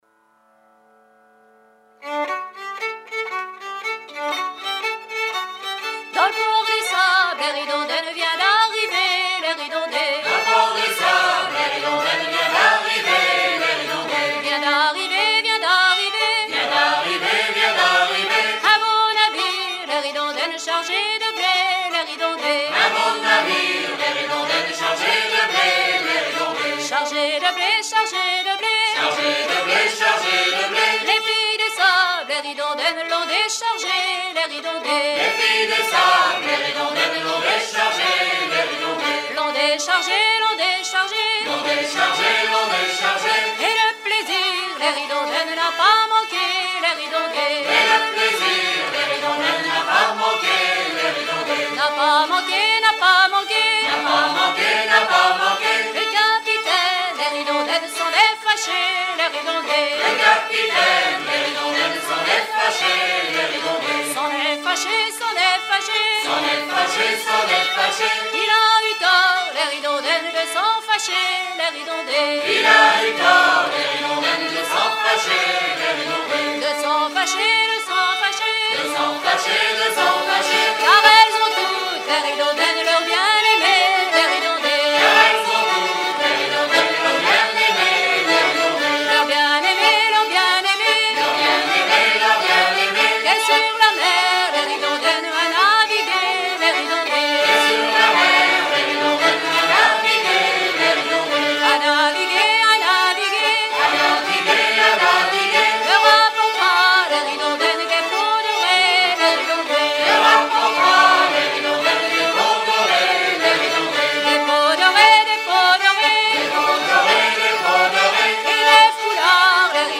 ronde à trois pas
Pièce musicale éditée